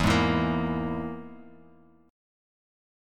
EMb5 chord